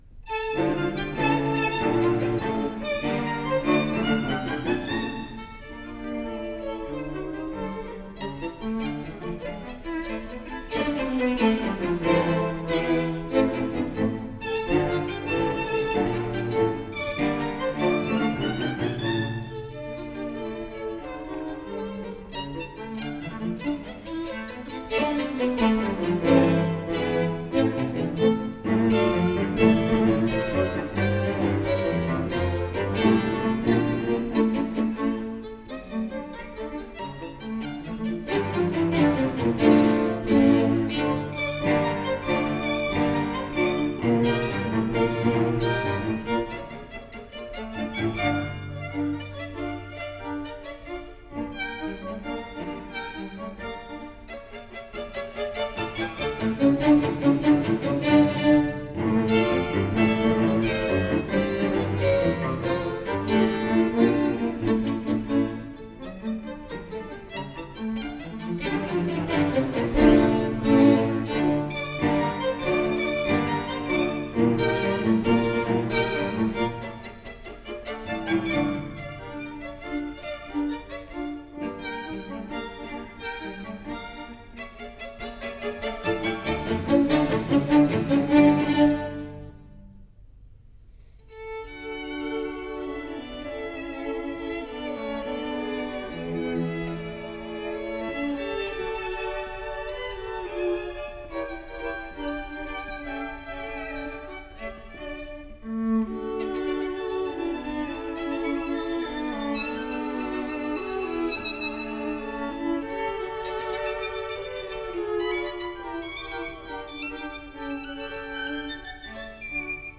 3ème mouvement (Scherzo